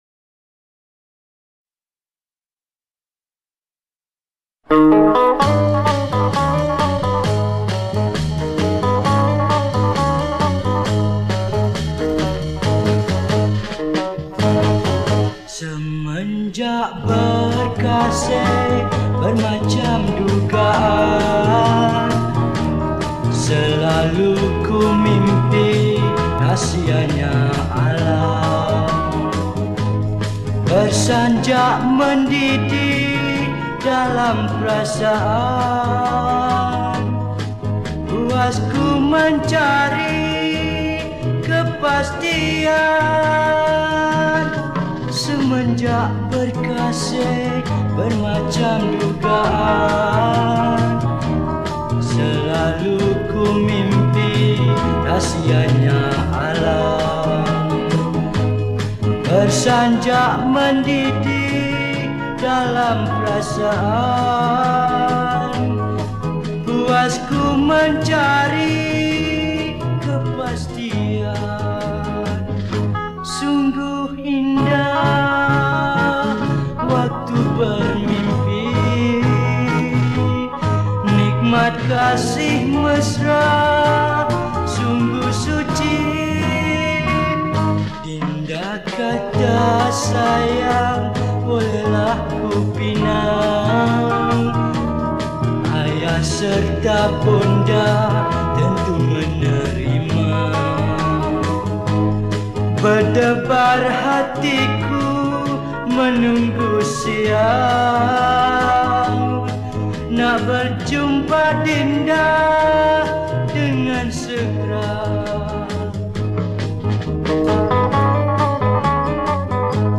Pop Yeh Yeh
Malay Song